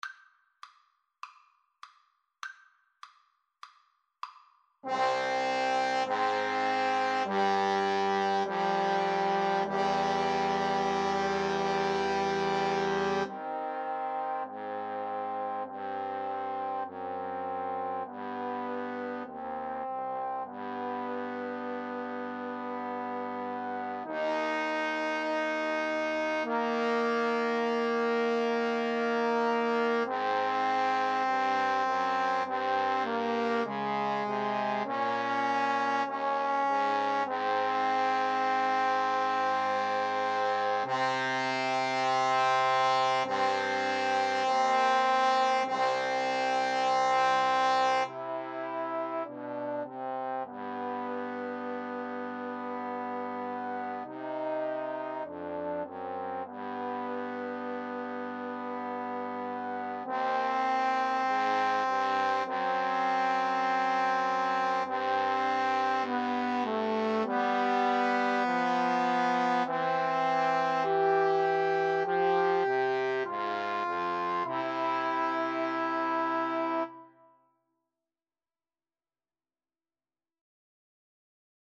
Eb major (Sounding Pitch) (View more Eb major Music for Trombone Trio )
Trombone Trio  (View more Easy Trombone Trio Music)
Classical (View more Classical Trombone Trio Music)